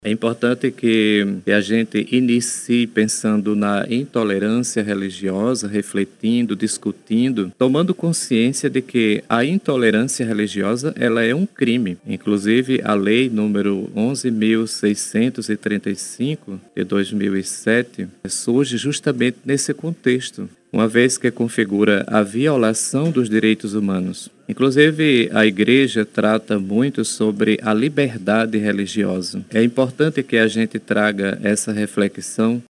Em entrevista à Rádio Rio Mar